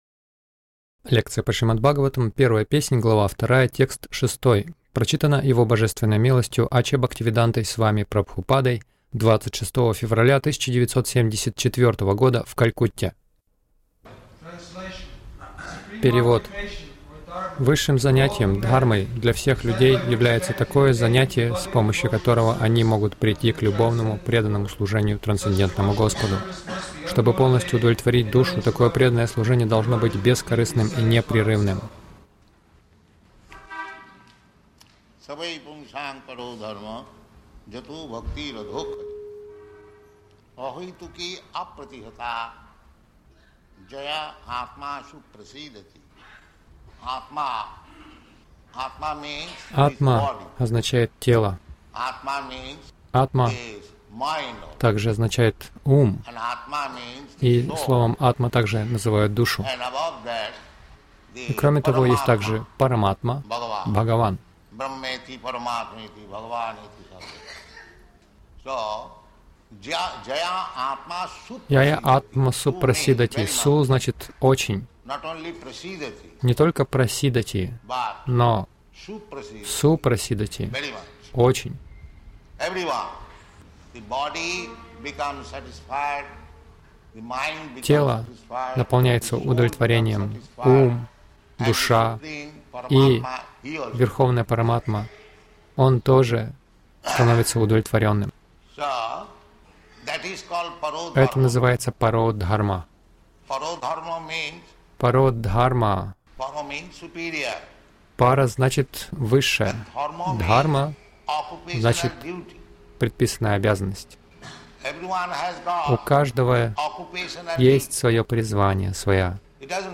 Милость Прабхупады Аудиолекции и книги 26.02.1974 Шримад Бхагаватам | Калькутта ШБ 01.02.06 — Варнашрама.